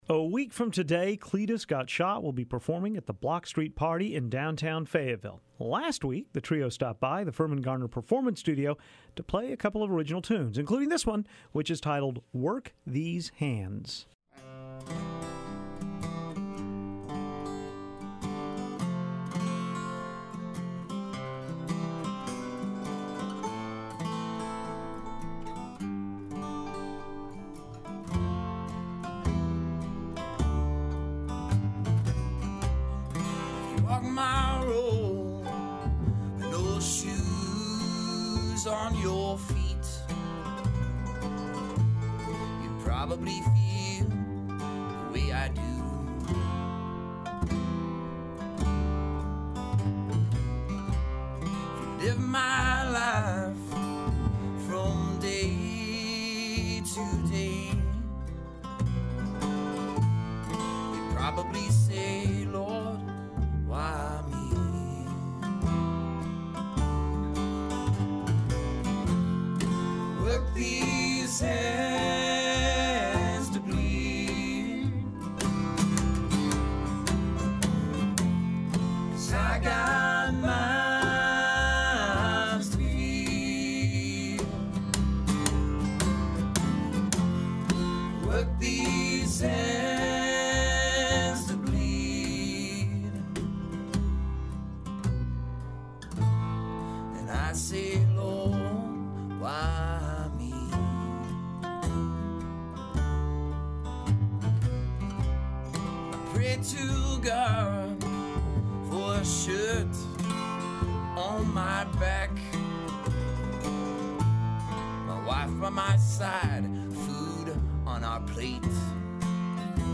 the trio performs